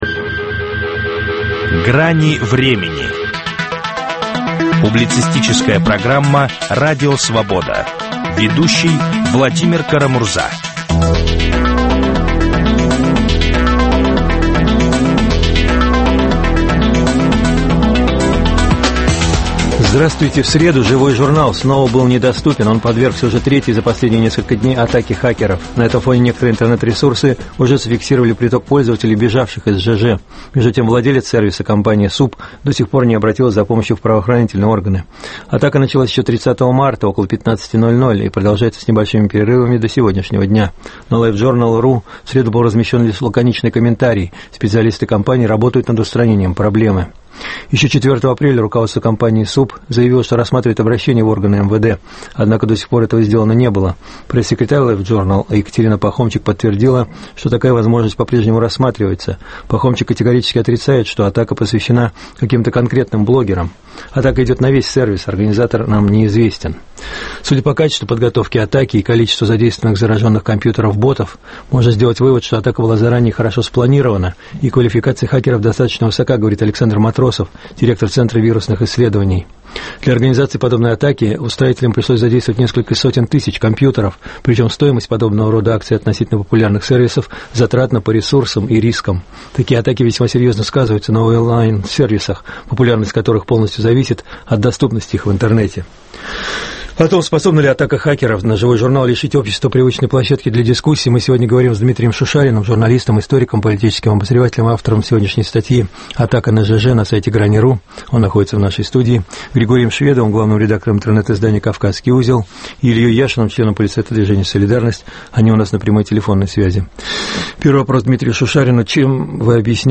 Способна ли атака хакеров на ЖЖ лишить общество привычной площадки для дискуссий? Об этом спорят популярные блогеры